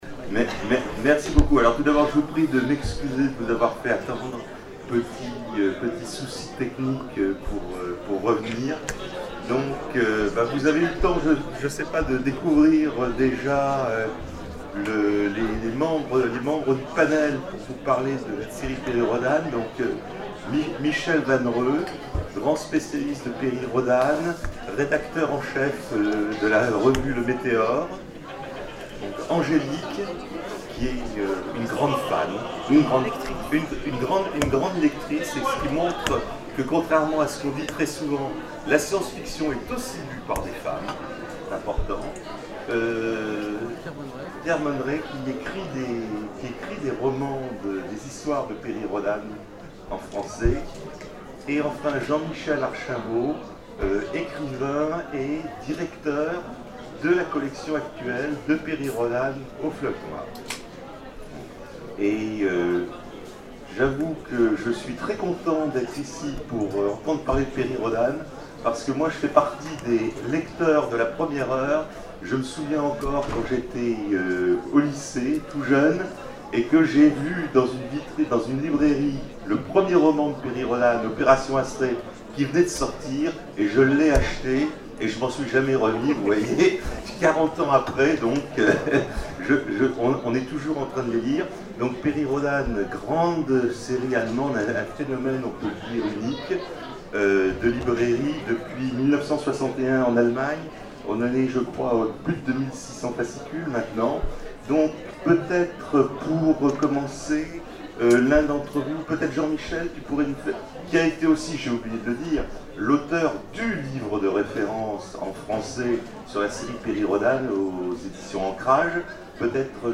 Zone Franche 2012 : Conférence Perry Rhodan
Table ronde